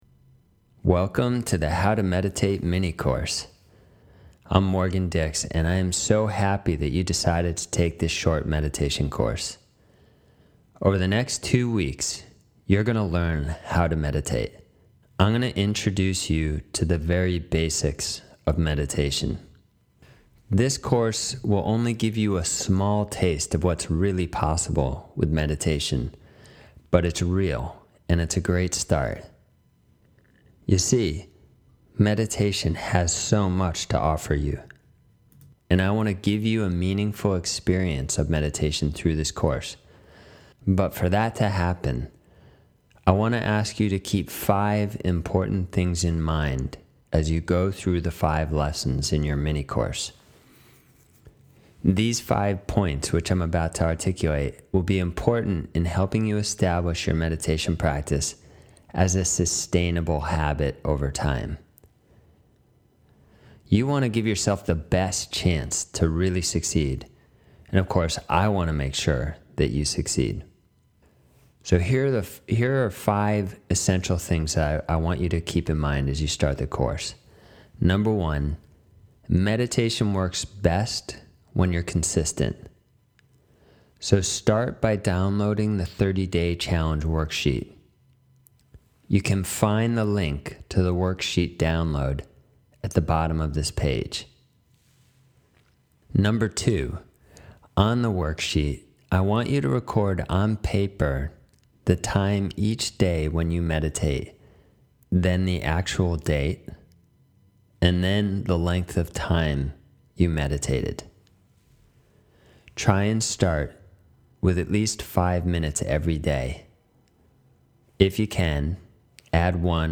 Audio Introduction (4min)